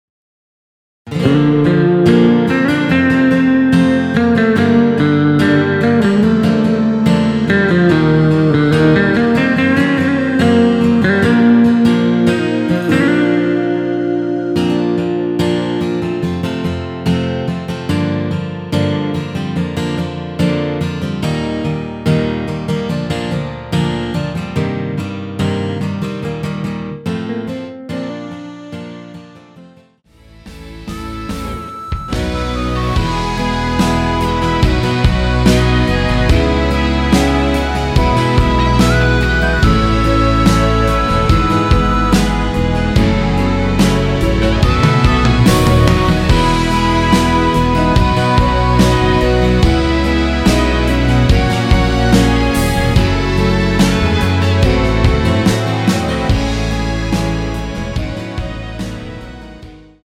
원키에서(-3)내린 MR입니다.
Bb
앞부분30초, 뒷부분30초씩 편집해서 올려 드리고 있습니다.